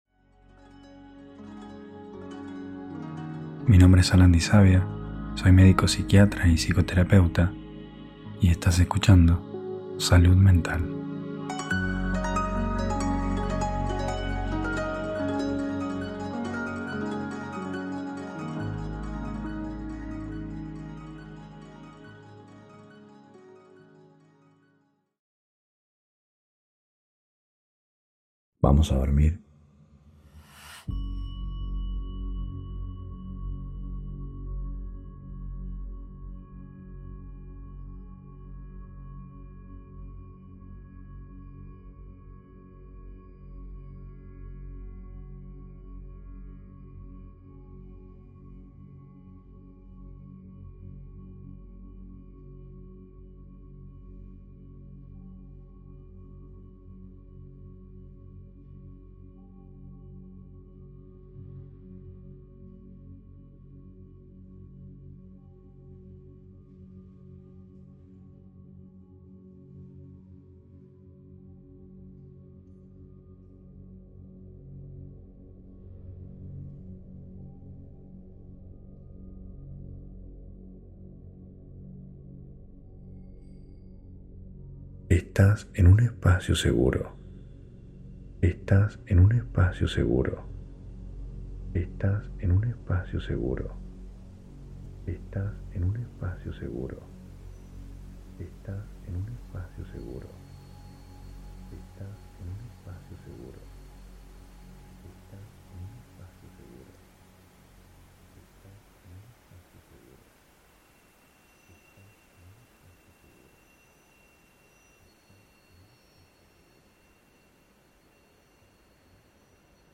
Hipnosis guiada para dormir.